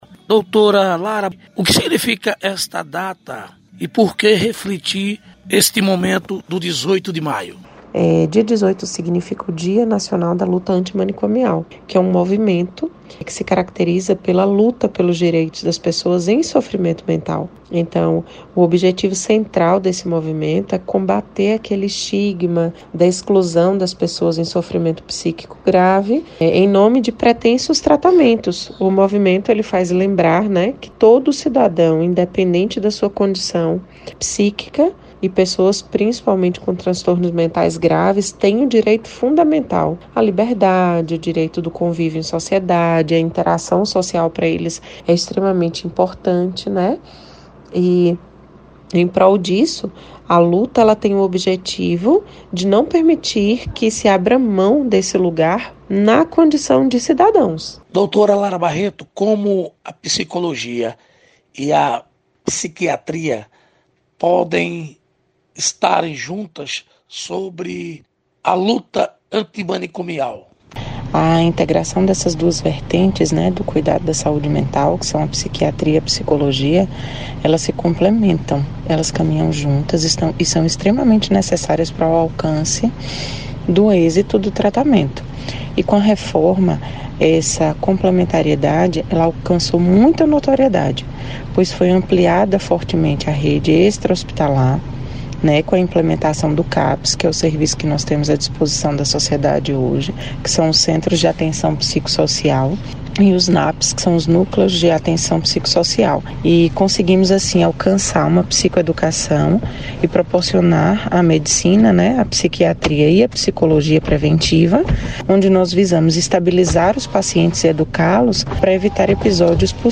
psiquiatra fala sobre a luta antimanicomial